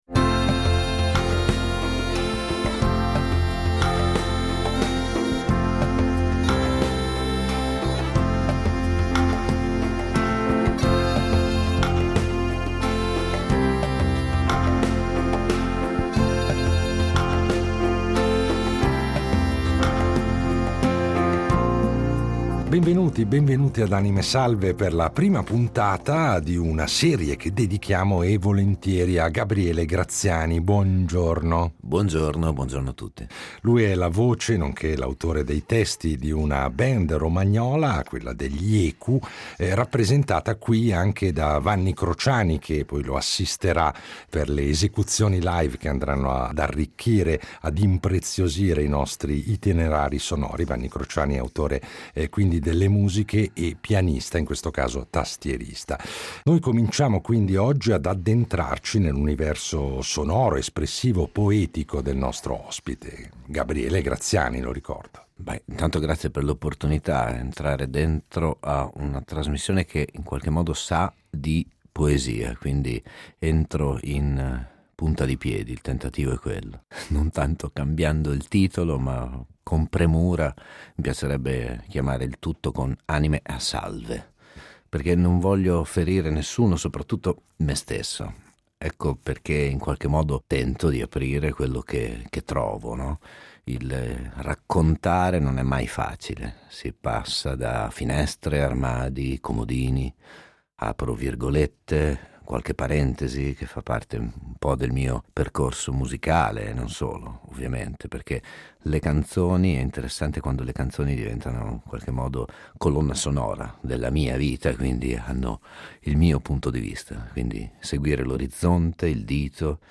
Cinque puntate per scoprire passioni e pensieri di un cantautore che, come sempre in "Anime Salve", ci regala alcune sue canzoni interpretate dal vivo.